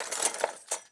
SFX_Metal Sounds_08.wav